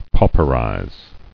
[pau·per·ize]